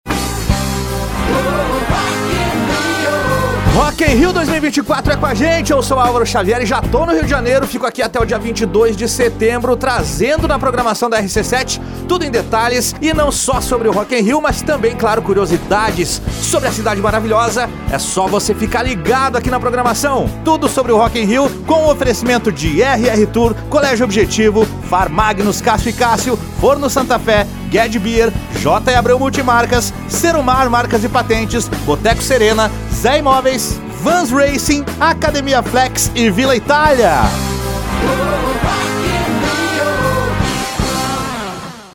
Spot Comercial
Impacto
Animada